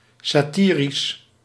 Ääntäminen
Synonyymit bijtend spottend satiriek Ääntäminen Tuntematon aksentti: IPA: /sa.ti.ɾis/ Haettu sana löytyi näillä lähdekielillä: hollanti Käännös Ääninäyte Adjektiivit 1. satiric UK 2. satirical Luokat Adjektiivit